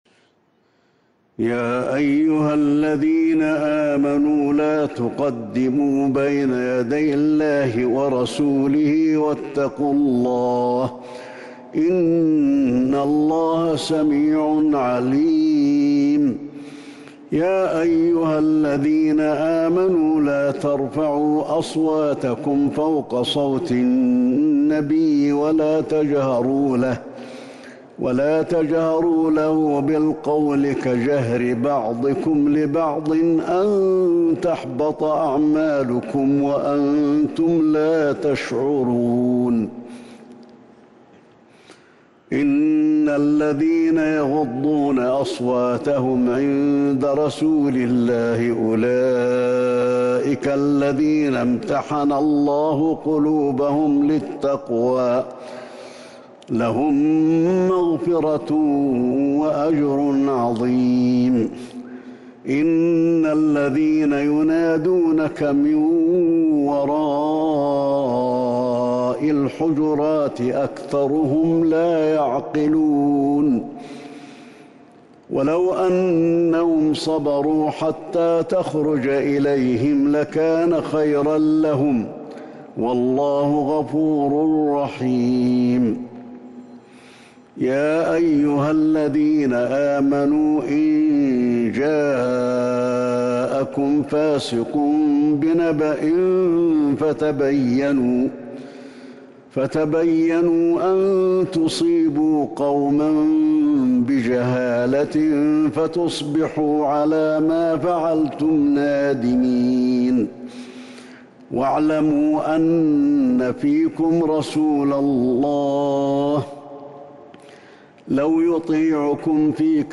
سورة الحجرات Surat Al-Hujurat من تراويح المسجد النبوي 1442هـ > مصحف تراويح الحرم النبوي عام 1442هـ > المصحف - تلاوات الحرمين